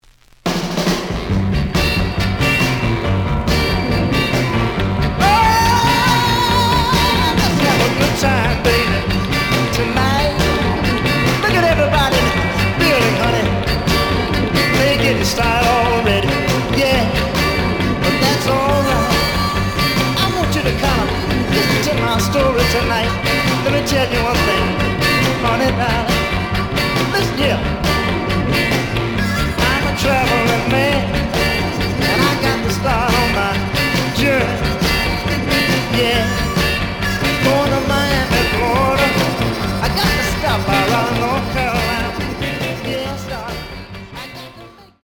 The audio sample is recorded from the actual item.
●Genre: Soul, 60's Soul
B side plays good.)